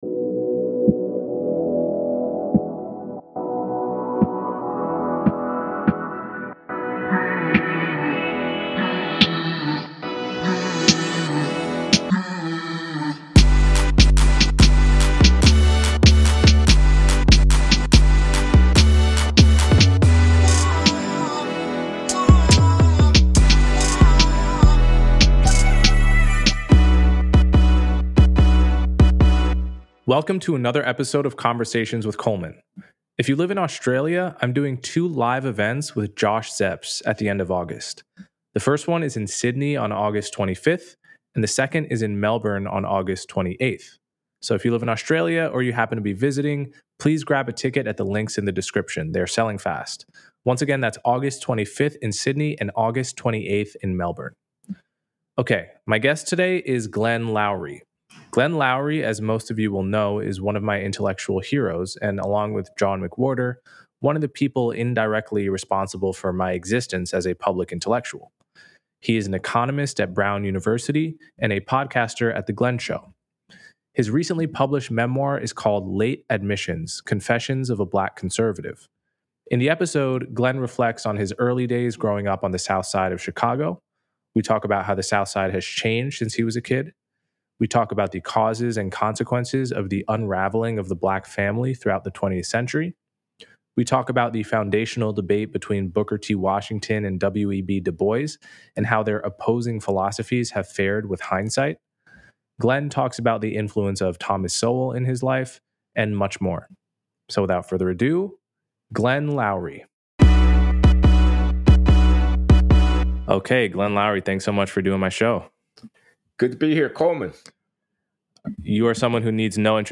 The conversation then shifts to the transformation of the South Side of Chicago over time and the unraveling of the Black family structure.